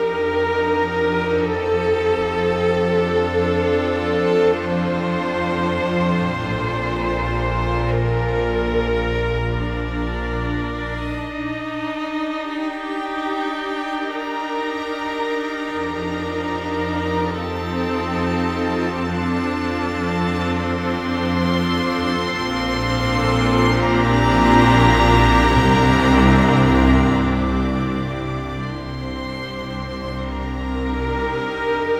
Rock-Pop 17 Strings 03.wav